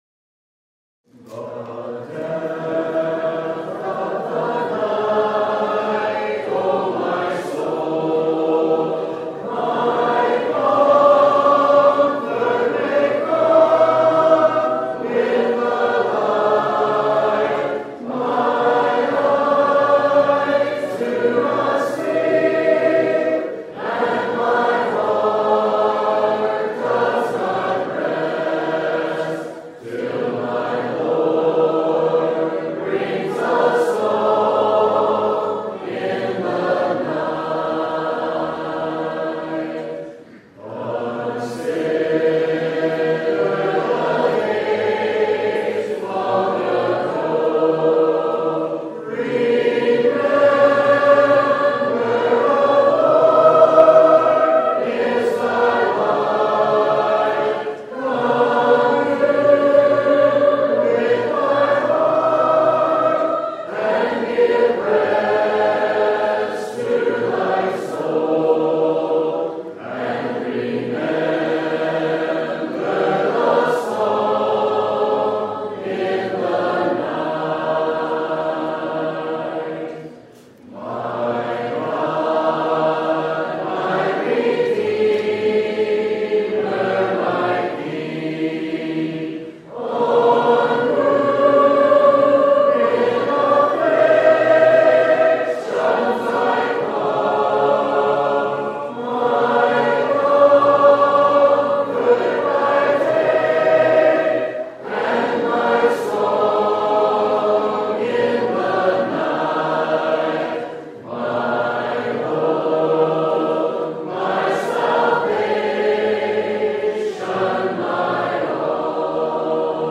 Congregational Singing, October 2020 (Individual Audio Recordings)
Please enjoy these recordings, formatted in individual tracks, from our October 2020 Anniversary Meeting.